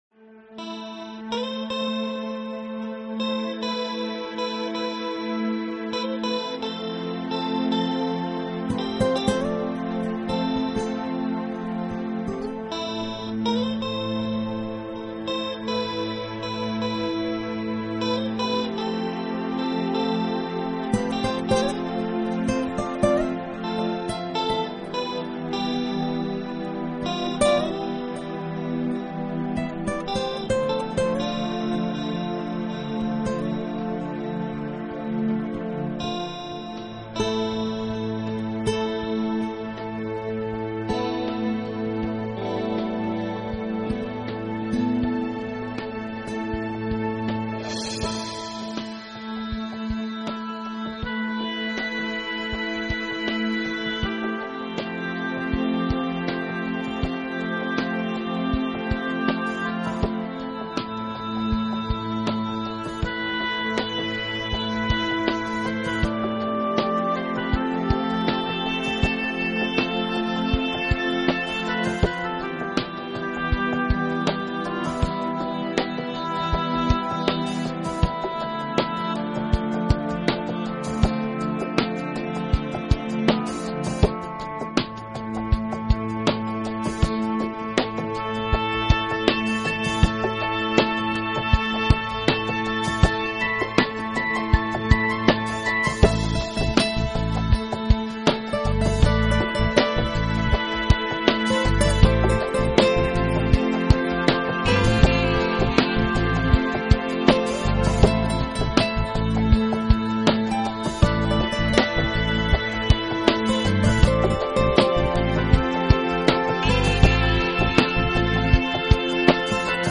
类型: Electronica